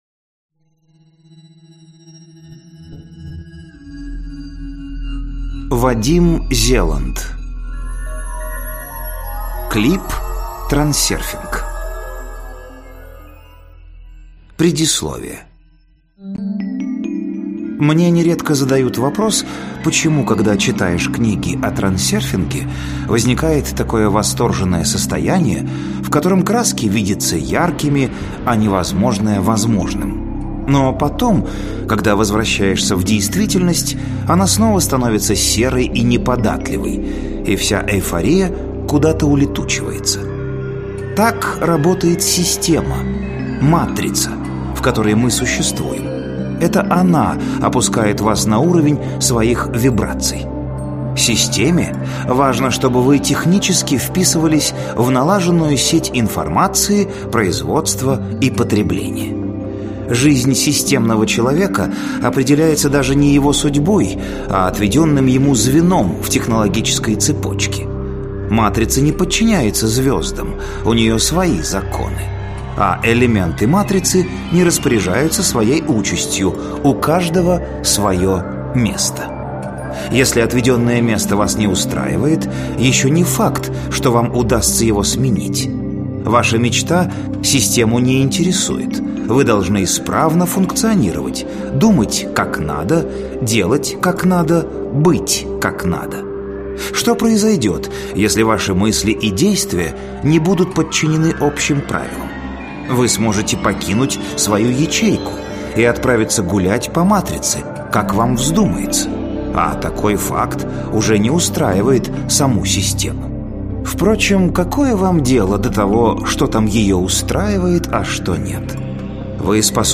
Аудиокнига Клип-трансерфинг. Принципы управления реальностью | Библиотека аудиокниг